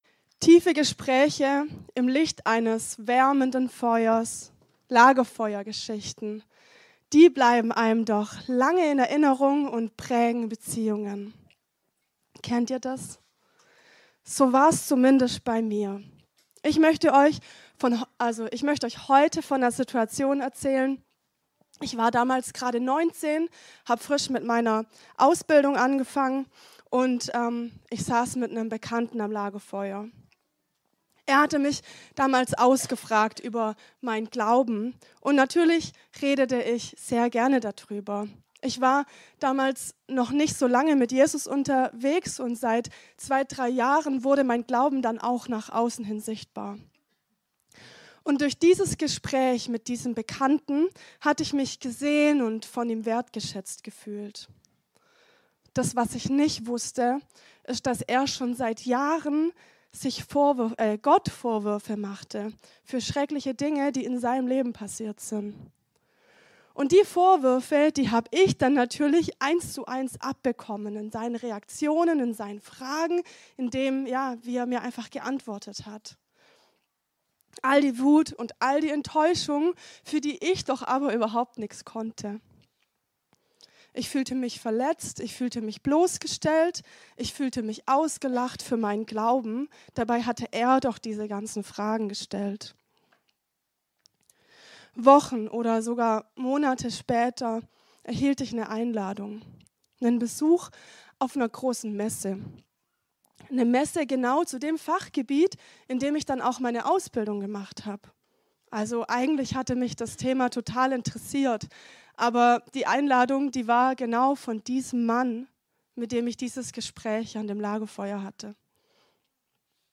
Eine predigt aus der serie "Weiße Weihnacht."